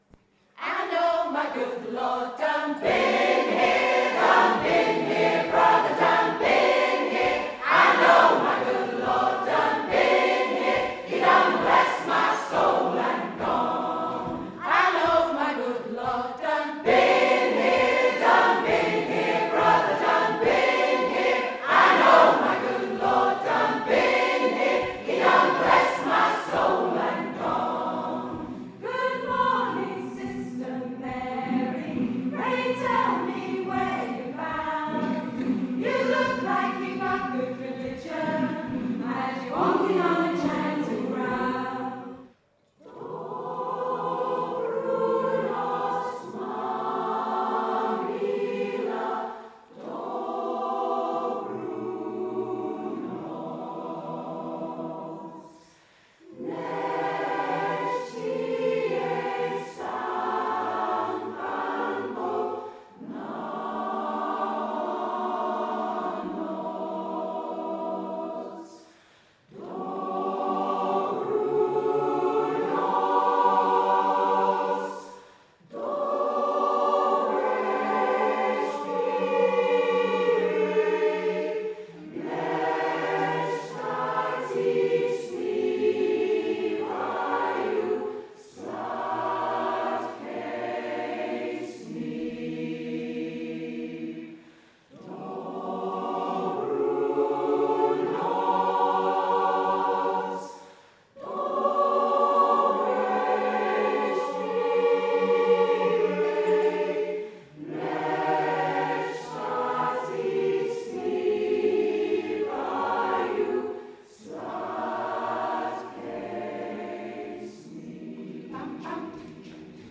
An Evening of Music and Song featuring Beverley Community Choir and the East Riding Suzuki Violin Group in aid of Beverley Community Lift and the Cherry Tree Community Association
Naturally, I’d thought the choir couldn’t possibly be their best without me, but oh no, they managed perfectly well, and at times the sound was sublime and profoundly moving.
What amazed me about the experience was, not only realising what a beautiful sound the choir produced, but seeing how relaxed and confident everyone appeared.
Summer-Concert-2014-Compilation.mp3